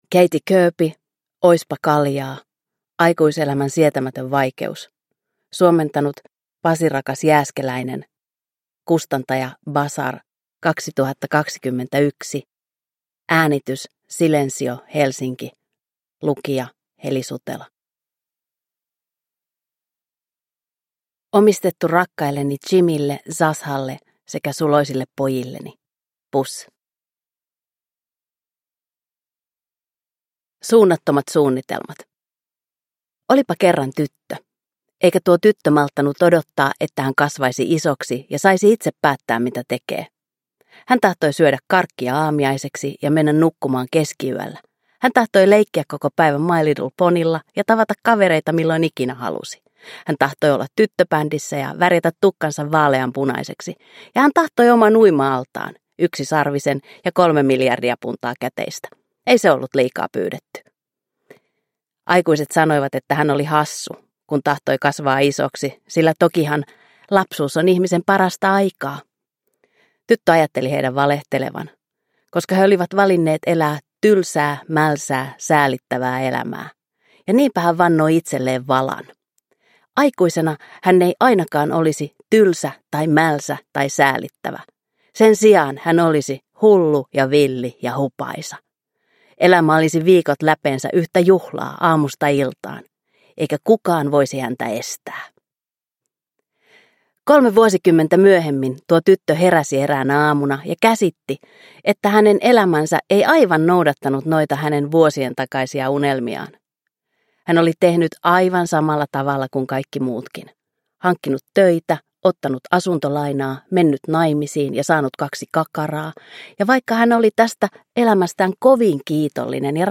Oispa kaljaa – Ljudbok